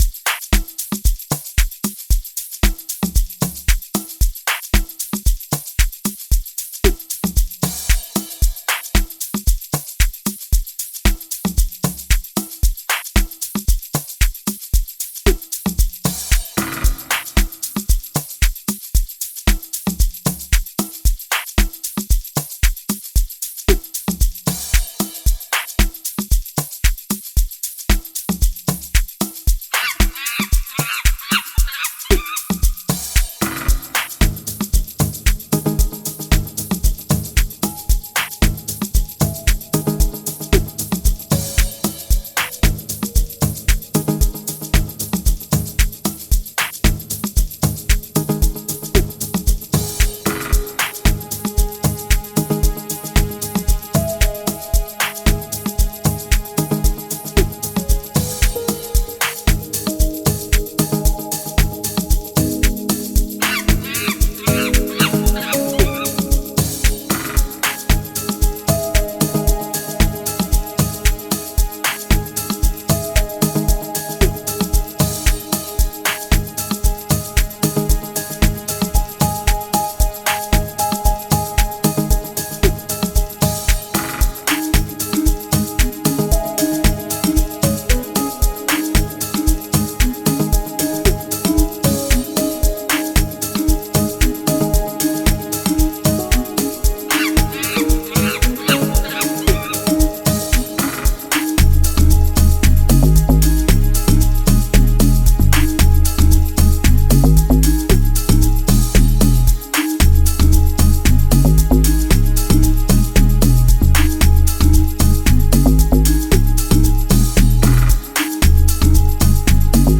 is an instrumental based production